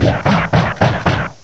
cry_not_crabominable.aif